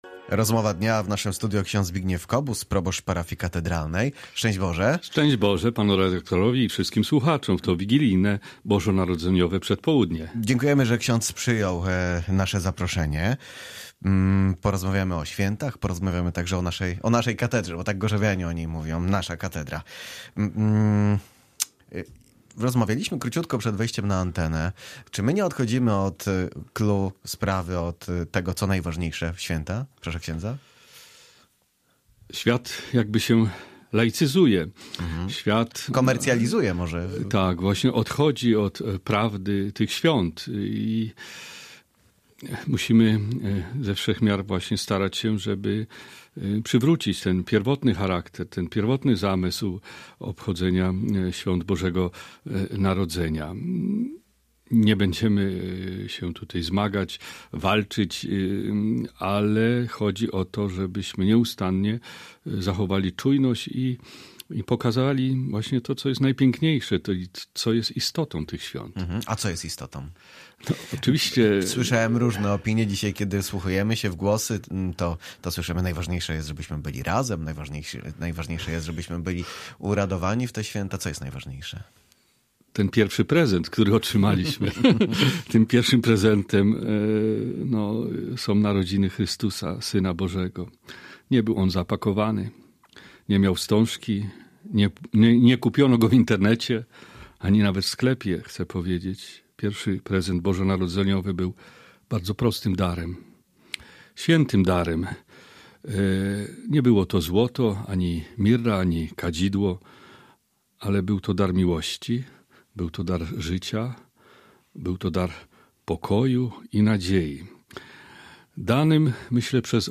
Gość na 95,6FM